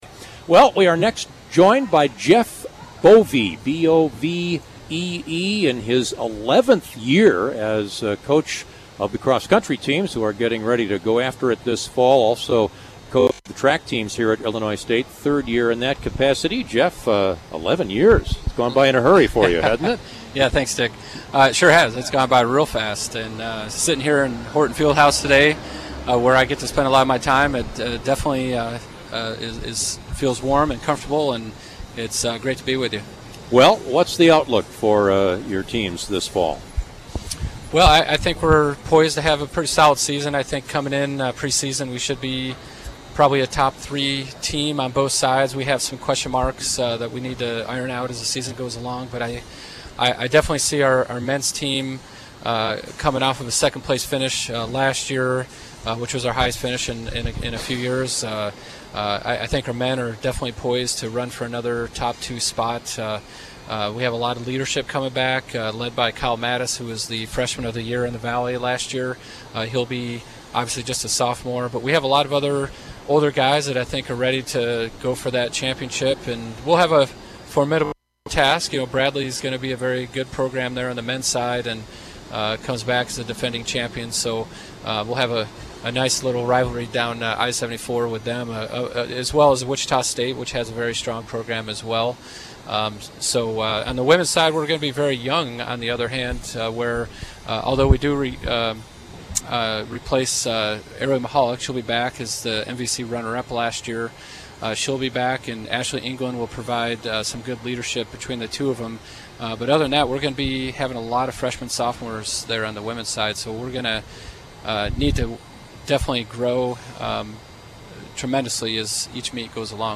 at the Redbird Preview Hog Roast